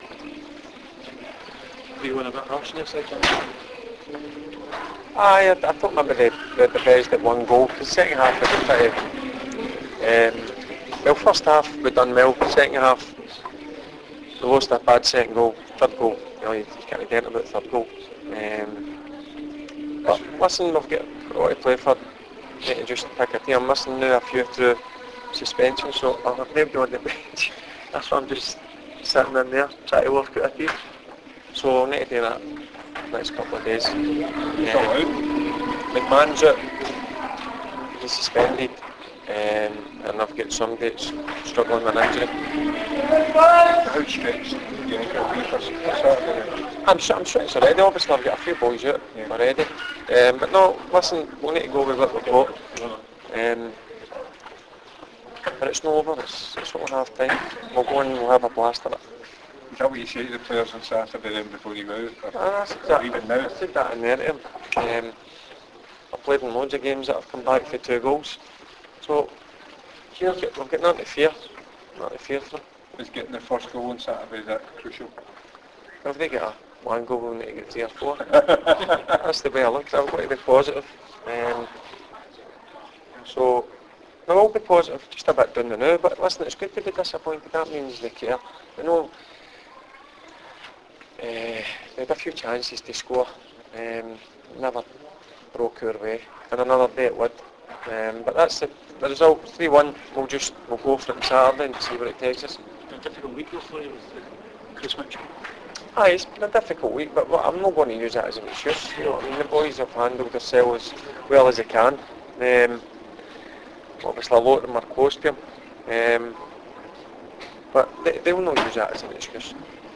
Barry Ferguson's press conference after the Ladbrokes League 1 play-off match.